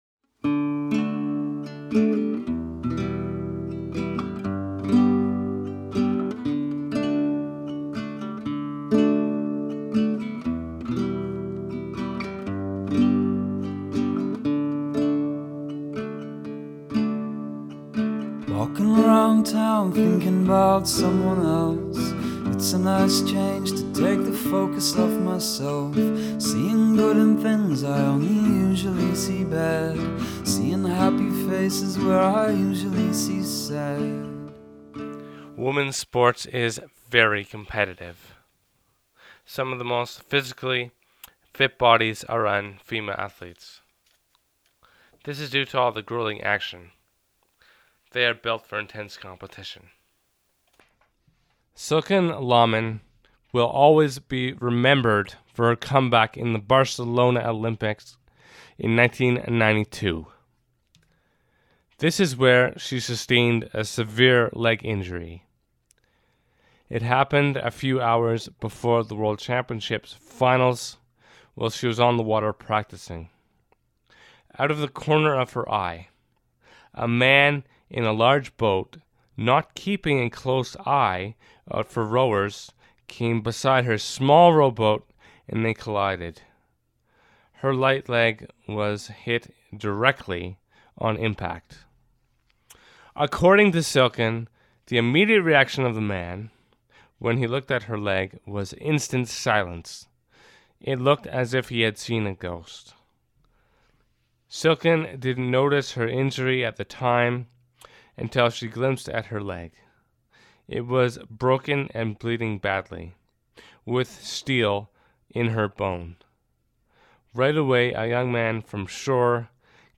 What Binds Us, a documentary about women in sport